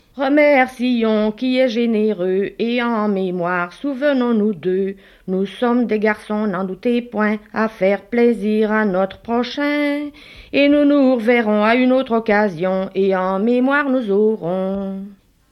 Genre : chant Type : chanson narrative ou de divertissement
Lieu d'enregistrement : Tilff (Esneux)
Support : bande magnétique